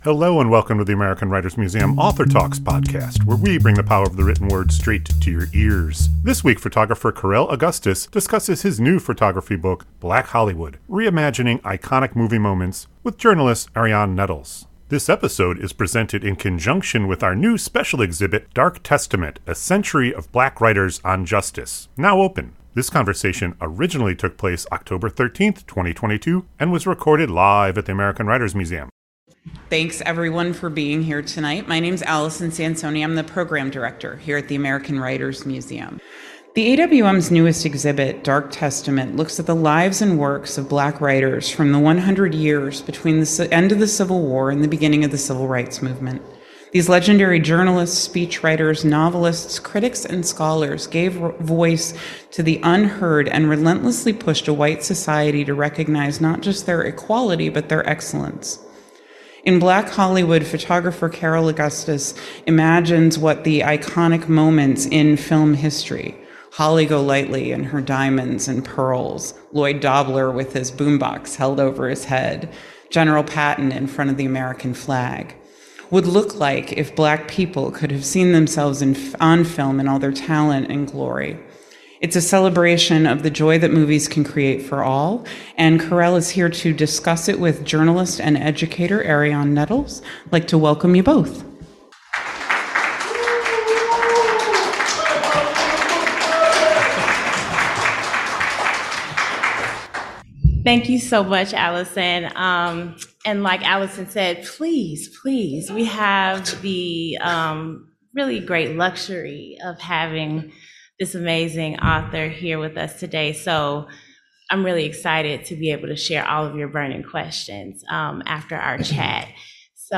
This conversation originally took place October 13, 2022 and was recorded live at [...]